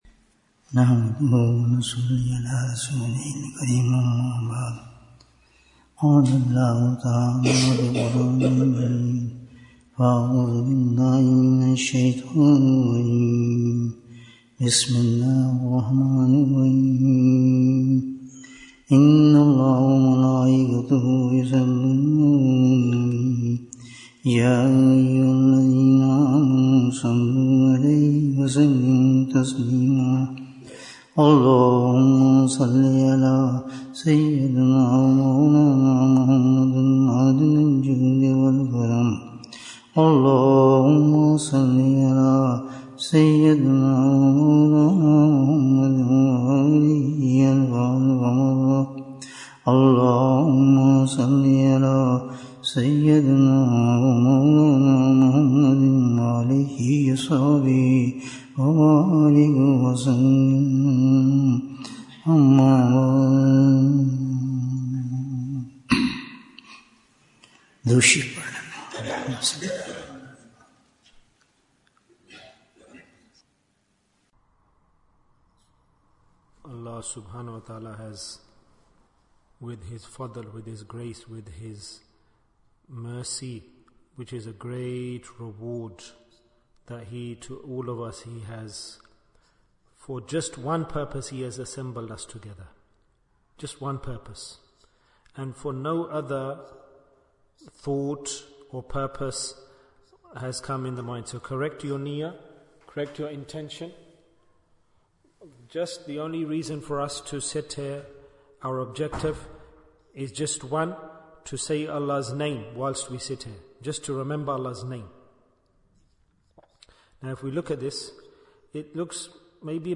How Can We Benefit From This Dunya? Bayan, 45 minutes11th July, 2024